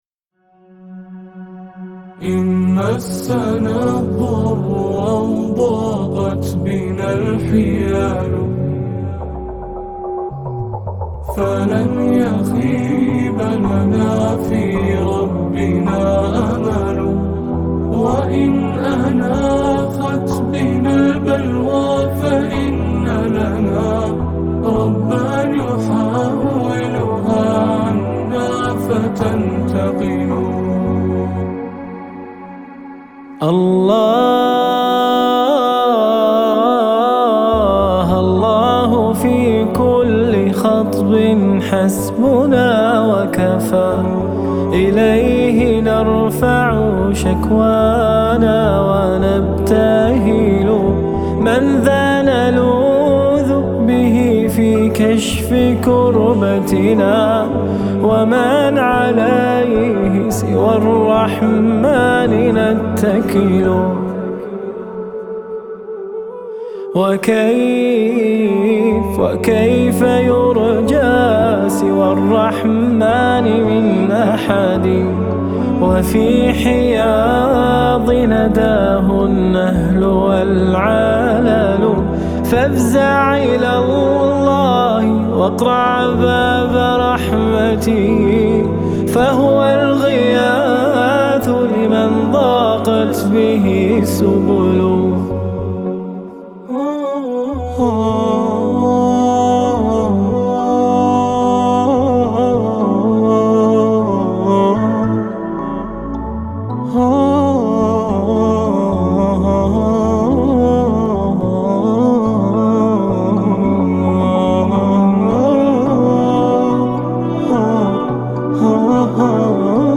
البوم كامل بدون موسيقى